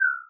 ethereal_chirp.ogg